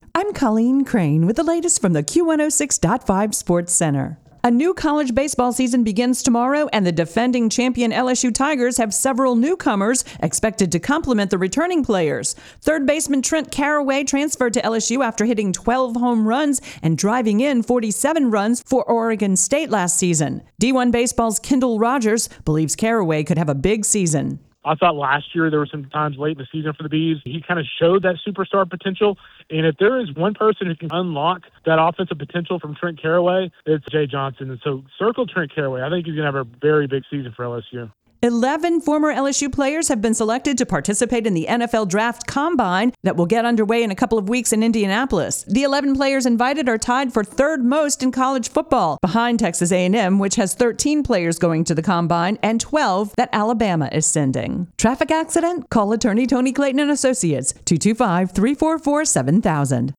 KQXL AM sportscast.mp3